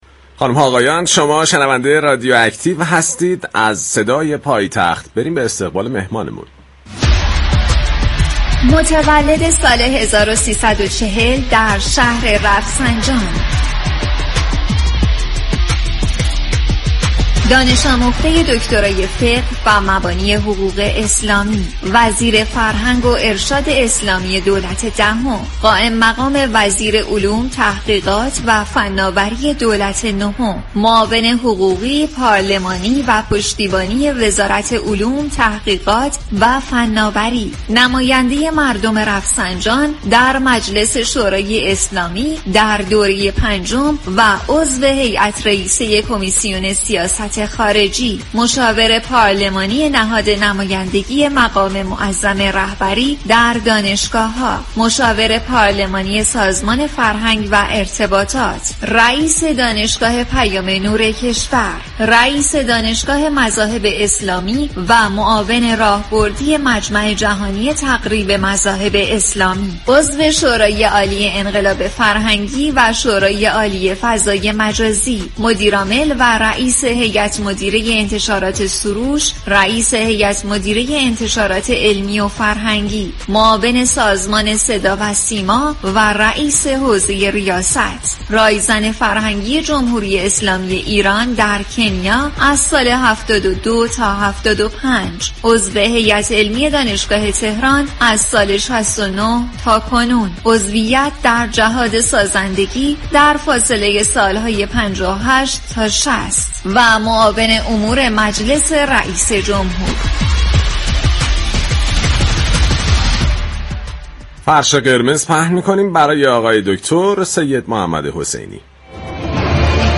نمایندگان باید منفعت مردم را به منفعت حزبی و شخصی ترجیح دهند به گزارش پایگاه اطلاع رسانی رادیو تهران، سید محمد حسینی معاون پارلمانی رئیس جمهور در گفت و گو با «رادیو اكتیو» در خصوص انتخابات پیش روی مجلس شورای اسلامی اظهار داشت: مجلس شورای اسلامی جایگاه والایی در نظام اسلامی دارد و نمایندگان مردم باید منفعت مردم و كشور را به منفعت حزبی و شخصی ترجیح دهند.